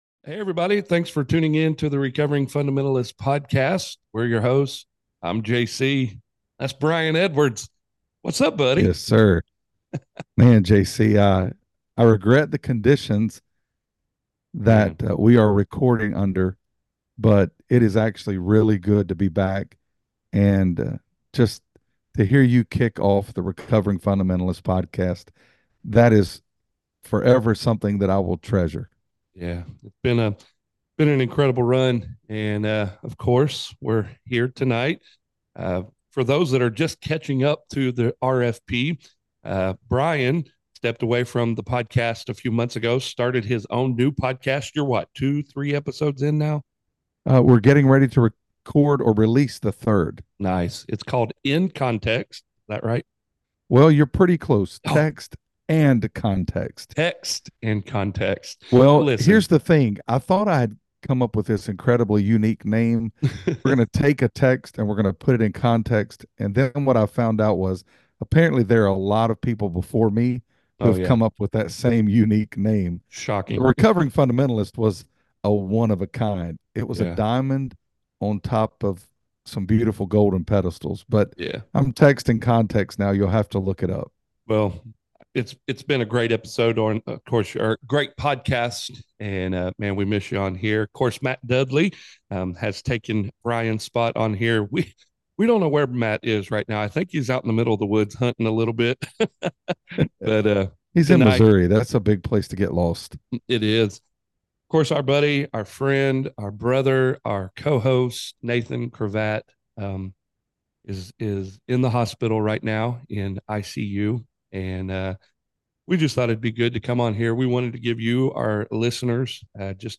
Join the conversation as three pastors who are recovering fundamentalists talk about life, ministry, and their journeys.